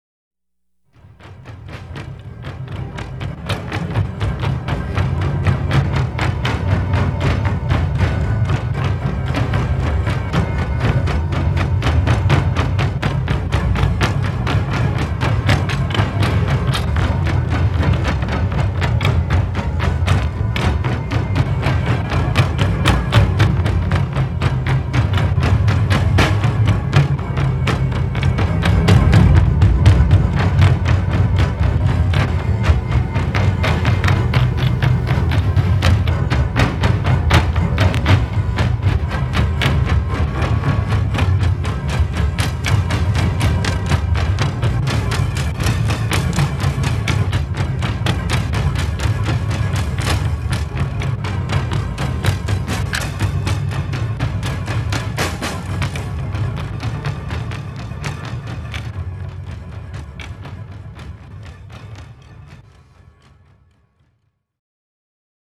This is the drumbeat from another song